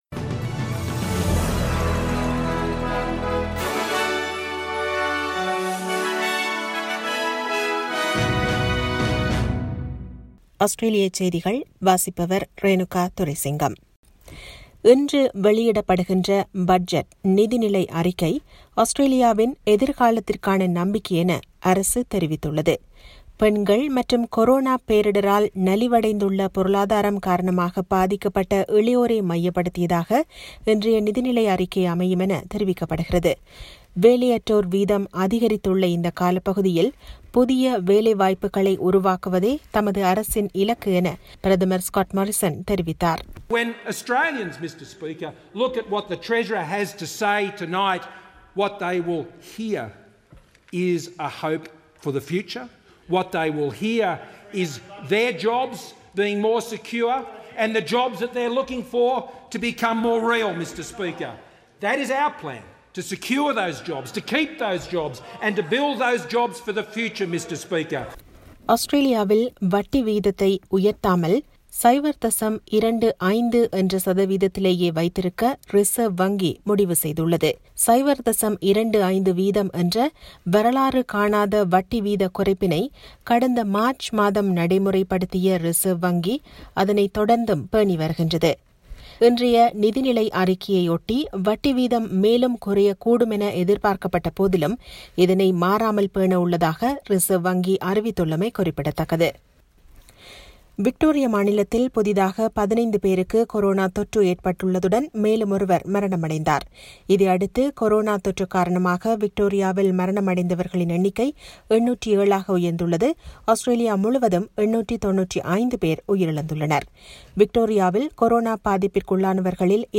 Australian news bulletin for Tuesday 06 October 2020.